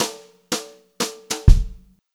120JZFILL2-L.wav